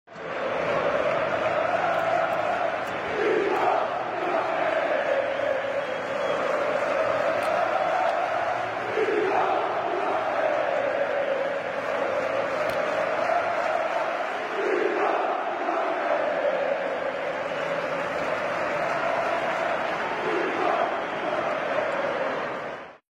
Same crowd, same group and sound effects free download
Same crowd, same group and same song This is SportingCP